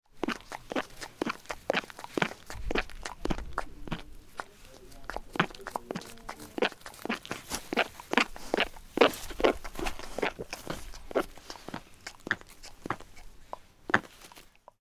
Звуки поросенка
Поросенок что-то уплетает